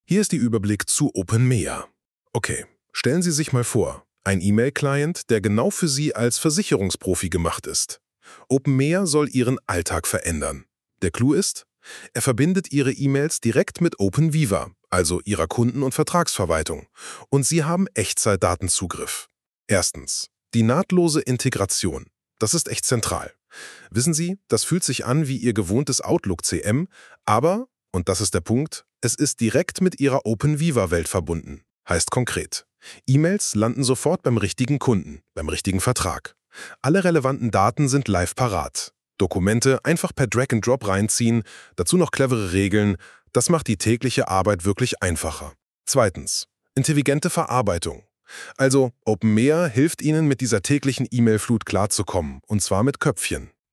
Modulgruppen openMIA Play Episode Pause Episode Mute/Unmute Episode Rewind 10 Seconds 1x Fast Forward 30 seconds 00:00 / 0:54 In neuem Fenster abspielen | Audiolänge: 0:54 Modulgruppen Entdecken Sie unsere Erfolgsgeschichten und Modulgruppen rund um openVIVA c2 – erklärt von einer künstlichen Intelligenz. Mit der Hilfe von Google NotebookLM haben wir ein Experiment gestartet und unsere Modulgruppen und Fallbeispiele praxisnah und detailliert von einer Large-Language-Model-KI erklären lassen. Tauchen Sie ein in den “ mbKompakt”-Podcast und informieren Sie sich über den Mehrwert von openVIVA c2. openMIA Wir sprechen in dieser Episode des mb Kompakt-Podcasts über unseren E-Mail-Gamechanger “openMIA”.